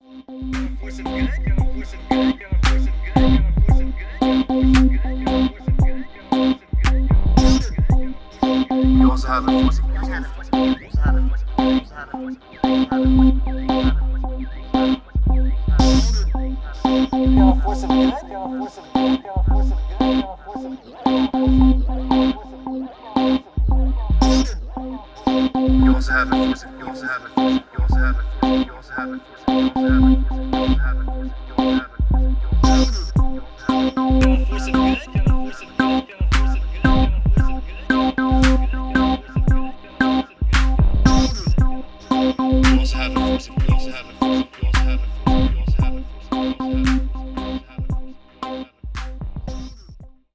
Mostly AR. First attempt at something slower and using soundbites from a D&D stream I watch which has a cool mechanic that allows watchers to influence gameplay (Force of Good/ Force of Evil) - The Dungeon Run.